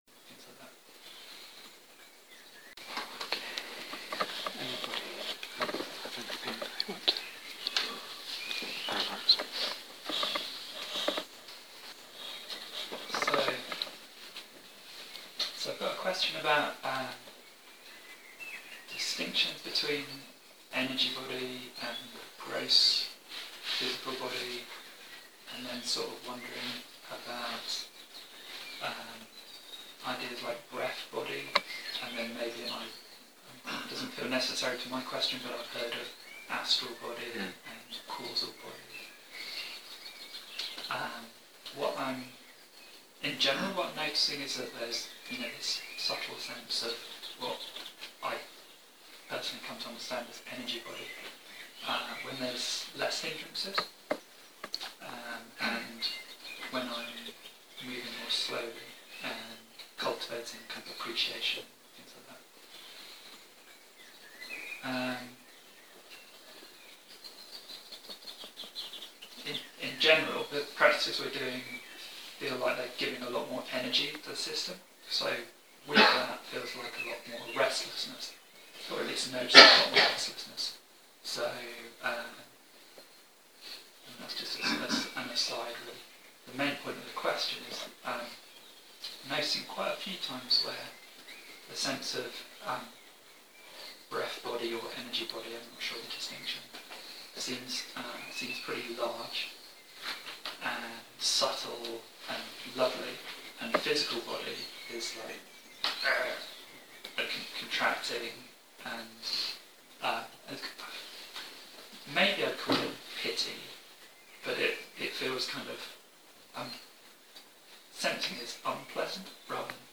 Energy Body and Image (Q & A)